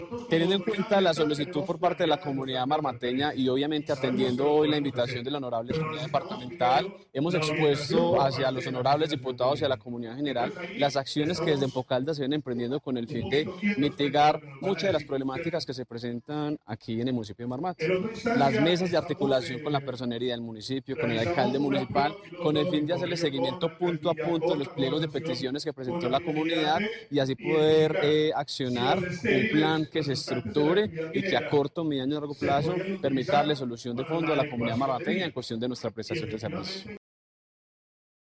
Gerente de Empocaldas, Cristian Mateo Loaiza.